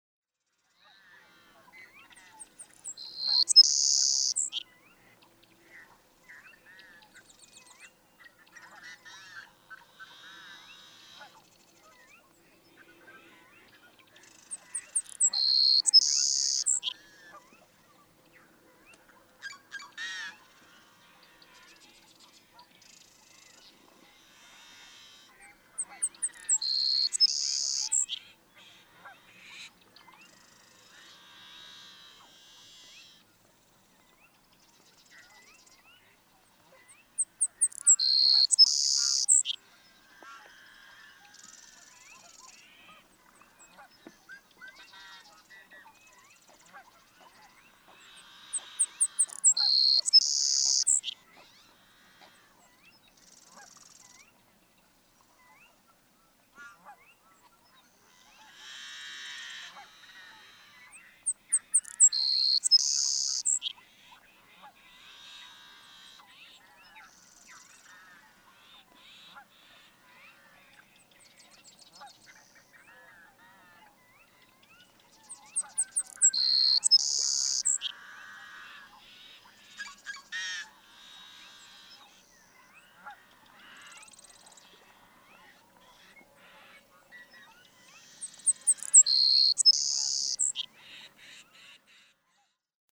Savannah sparrow
♫389. Example 2. June 10, 2008. Arapaho National Wildlife Refuge, Walden, Colorado. (1:32)
389_Savannah_Sparrow.mp3